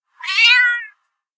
cat_meow_strong2.ogg